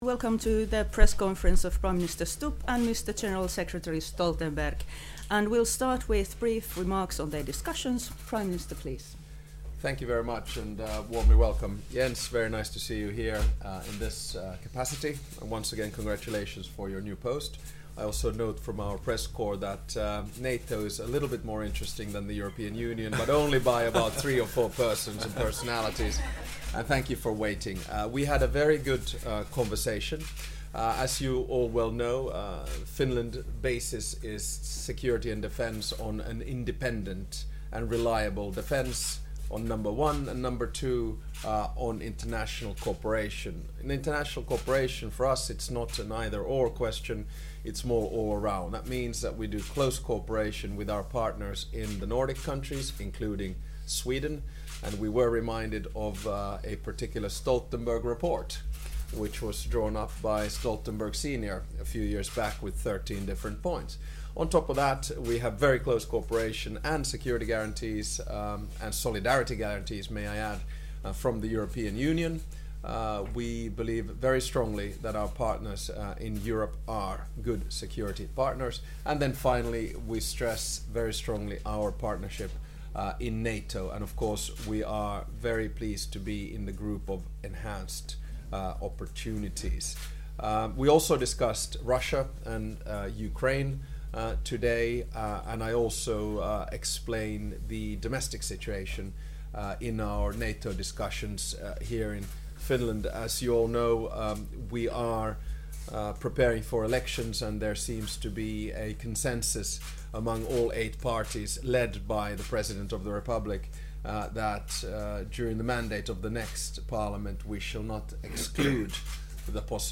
Opening remarks by NATO Secretary General Jens Stoltenberg at the joint press point with the Prime Minister of Finland, Alexander Stubb (followed by Q&A session)